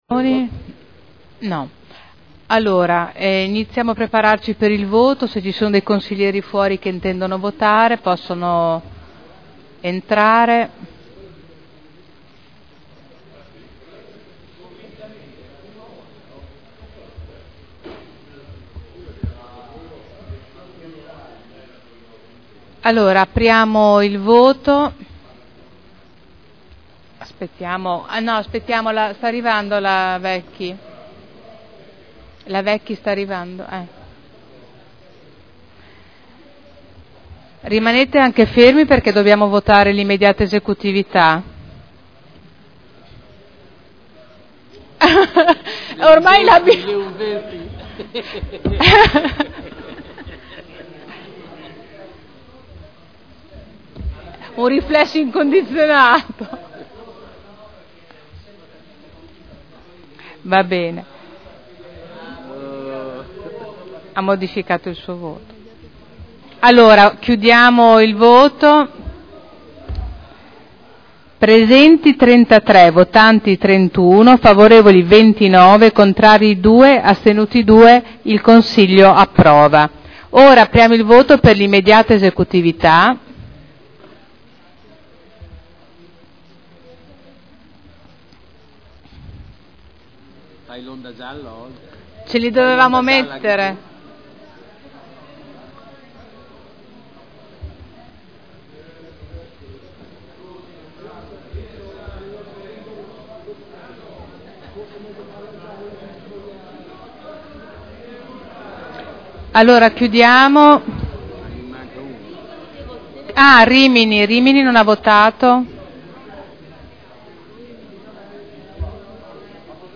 Seduta del 20/02/2012 Il Presidente metti ai voti la Delibera di Proroga concessione di garanzia fideiussoria a favore di Unicredit Banca Spa per apertura di credito e mutuo della Fondazione Casa di Enzo Ferrari Museo (Commissione consiliare del 7 febbraio 2012).